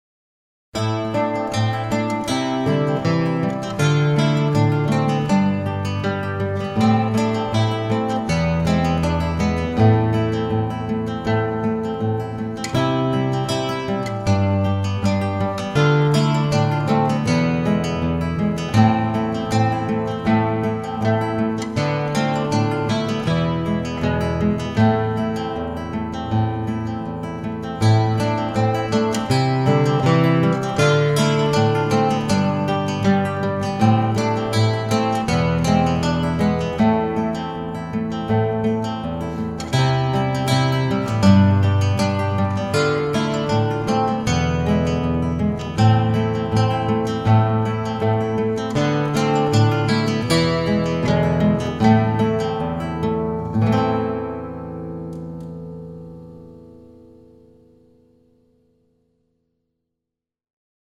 Gitarre, akustische Gitarre
Klassischer Stil